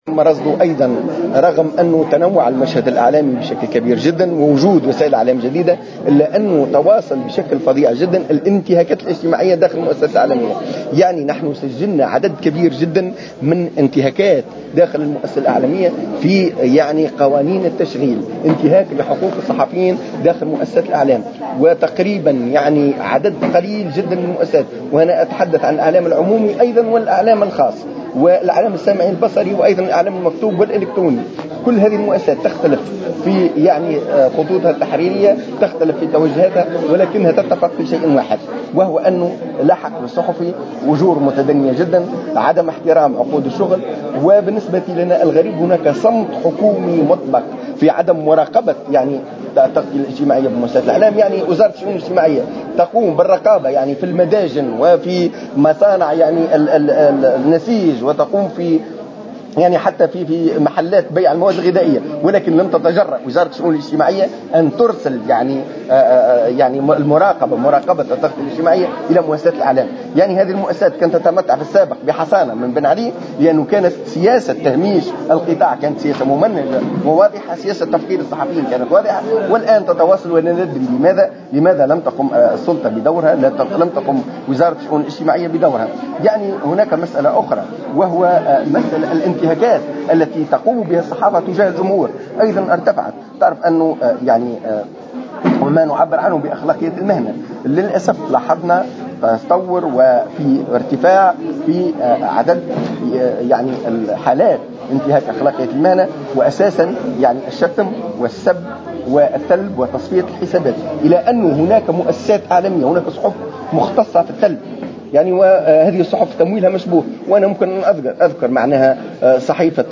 عقدت النقابة الوطنية للصحفيين التونسيين اليوم السبت ندوة صحفية لتقديم التقرير السنوي للحريات والميثاق الأخلاقي للتعاطي مع قضايا الإرهاب بمناسبة اليوم العالمي لحرية الصحافة.